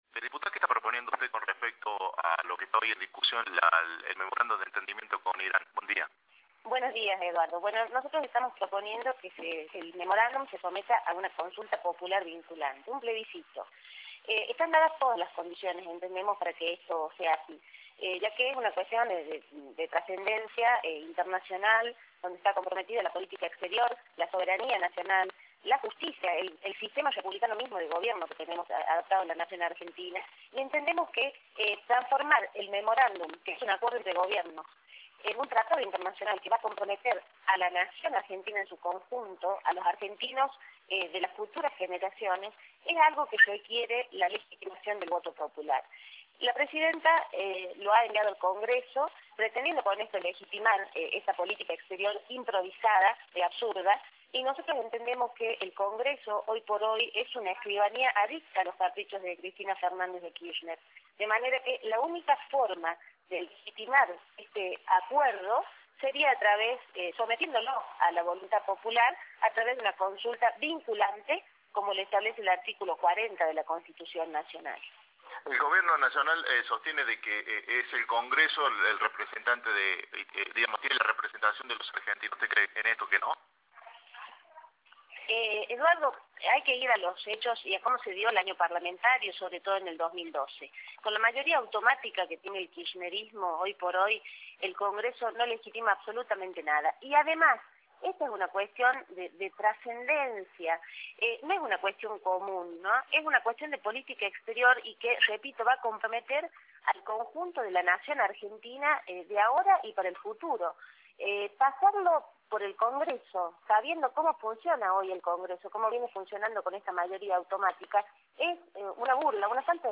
Inés Brizuela y Doria, diputada nacional, por Cadena 3 Argentina